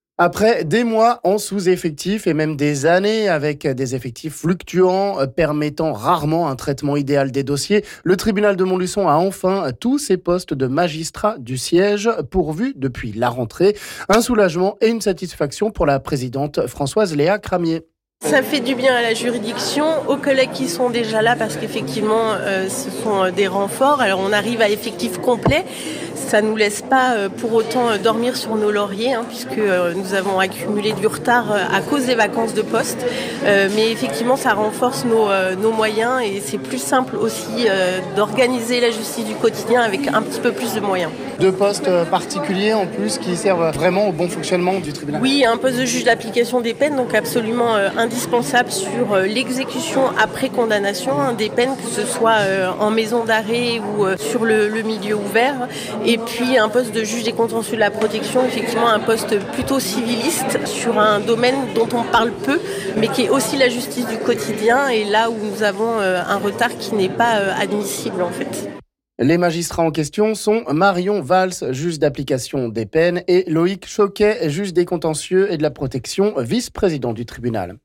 La présidente du tribunal Françoise-Léa Cramier nous en parle...